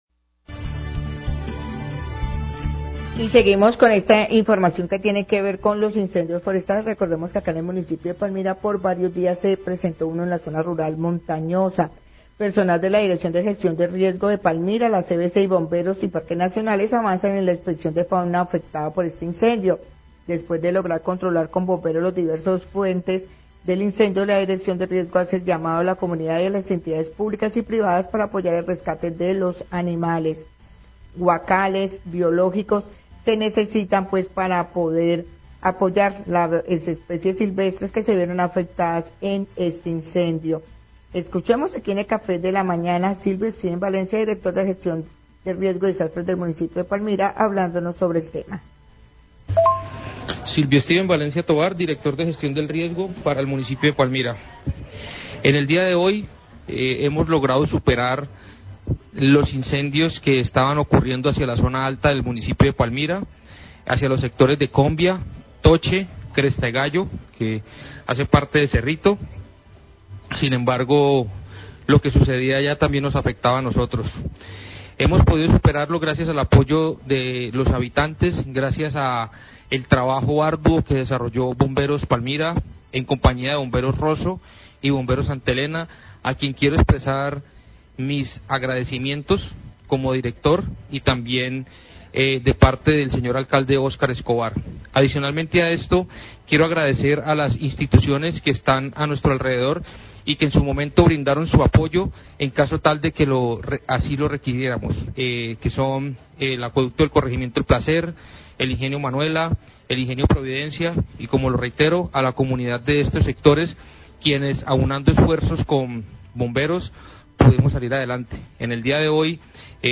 Radio
Silvio Stiven Valencia, director de la Oficina de Gestión del Riesgo de Palmira, habla de las afectaciones por los incendios forestales en zona rural de este municipio y la revisión que realiza CVC y Parques Nacionales de la fauna silvestre y flora afectada por las llamas.